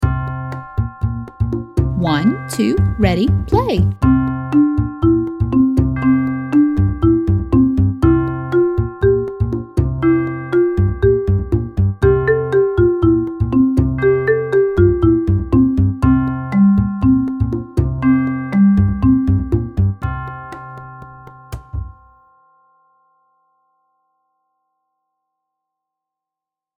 WITH INTRO